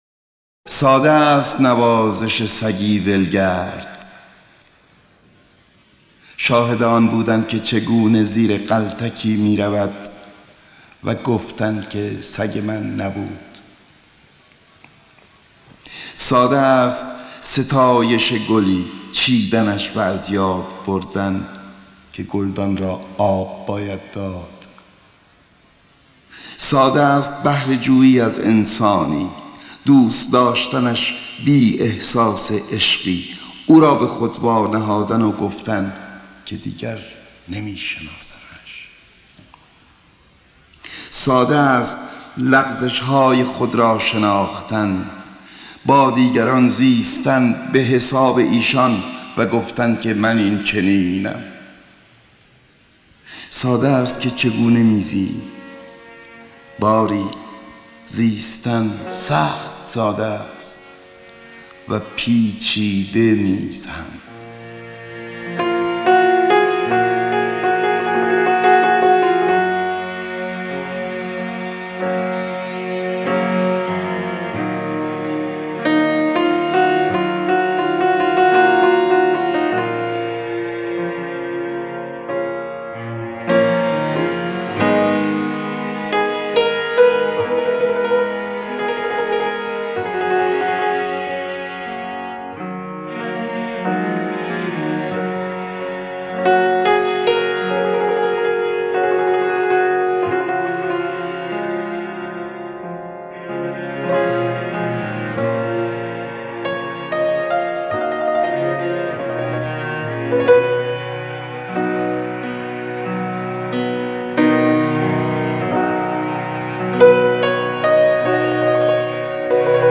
دانلود دکلمه ساده است نوازش با صدای احمد شاملو با متن دکلمه
گوینده :   [احمد شاملو]